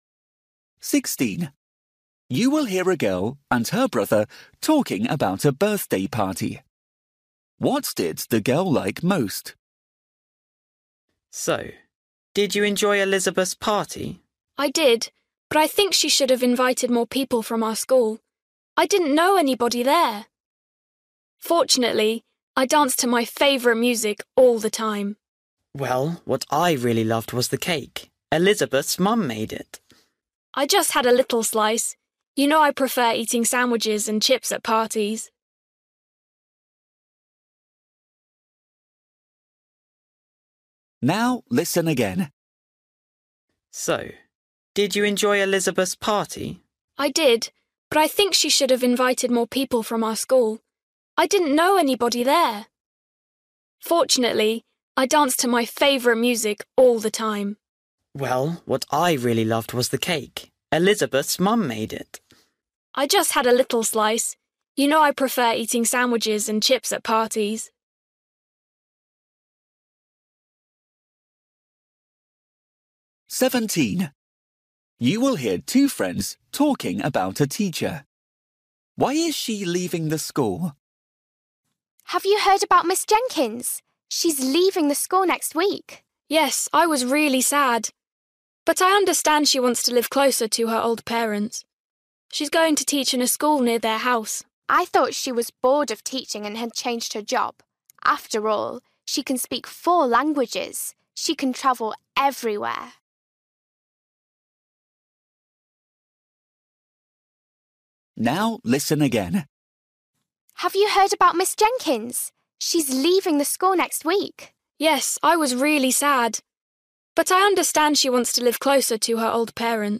Listening: everyday short conversations
16   You will hear a girl and her brother talking about a birthday party. What did the girl like most?
17   You will hear two friends talking about a teacher. Why is she leaving the school?